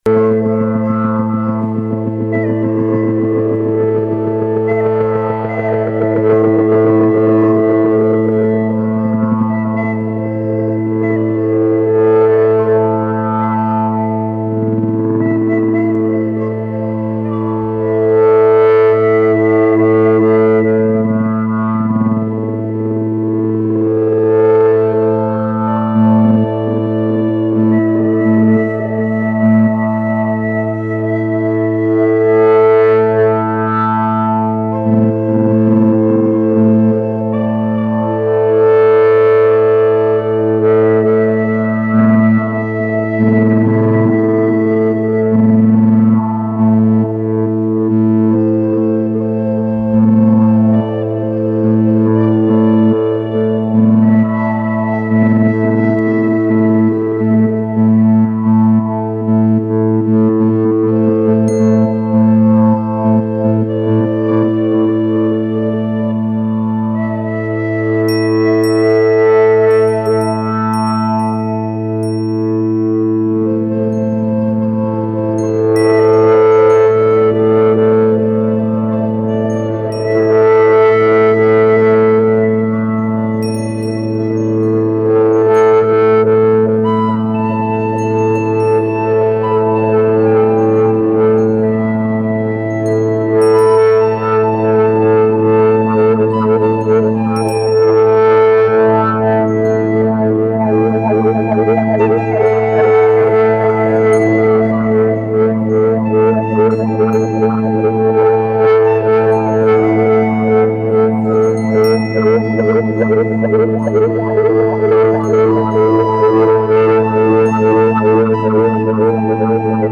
Rooted in Swedish psychedelic folk music